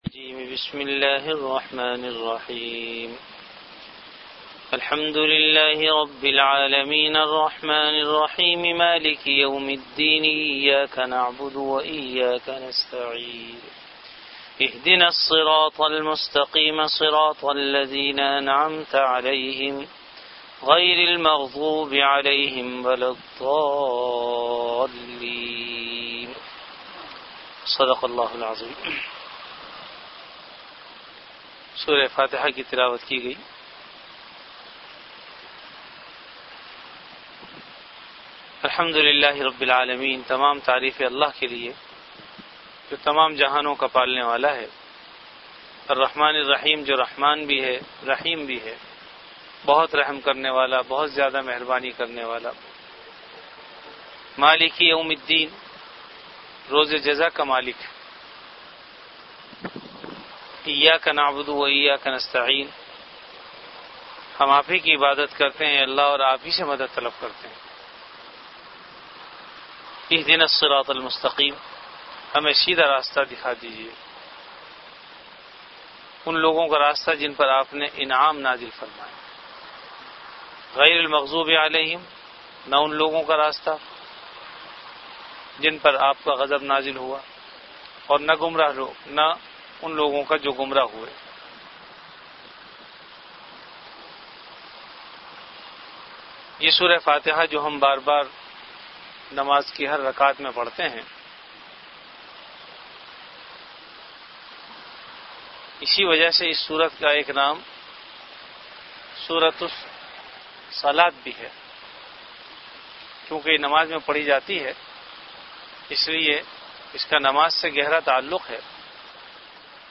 Dars-e-quran · Jamia Masjid Bait-ul-Mukkaram, Karachi
Category Dars-e-quran
Event / Time After Isha Prayer